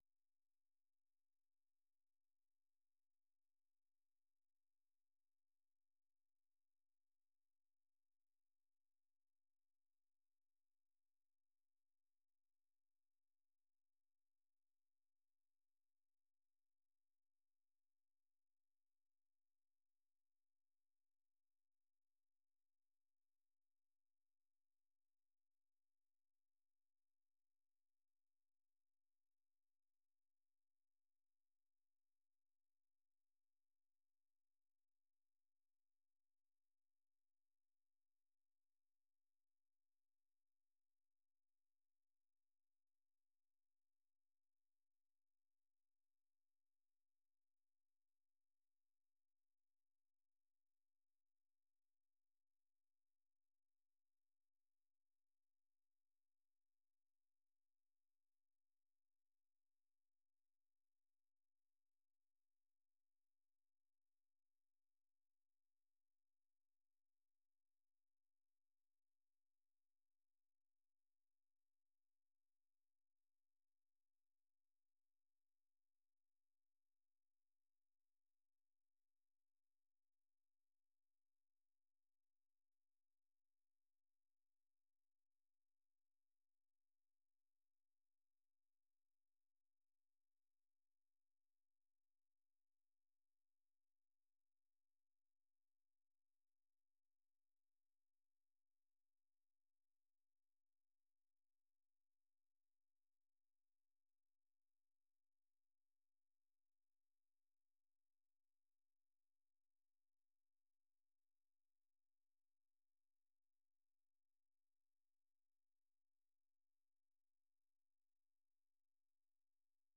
세계 뉴스와 함께 미국의 모든 것을 소개하는 '생방송 여기는 워싱턴입니다', 저녁 방송입니다.